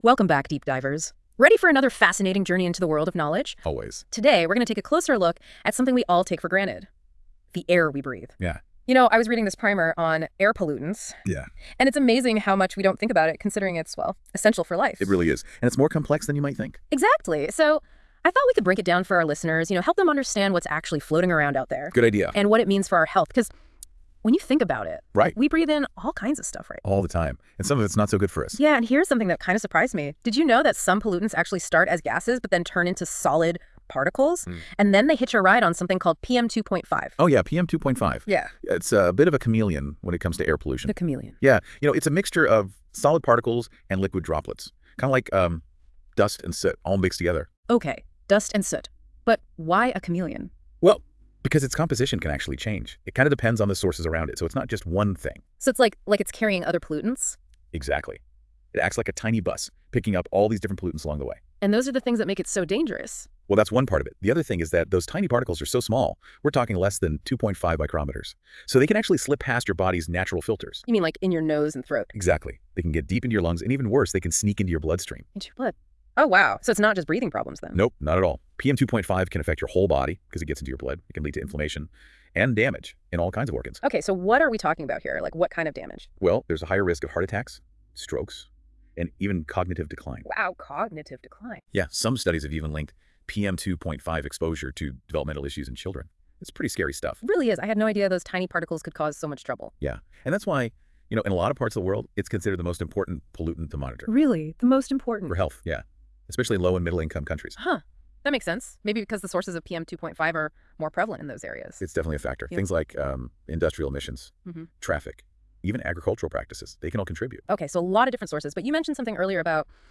Listen to a short podcast on the primer (auto-generated using notebook LLM)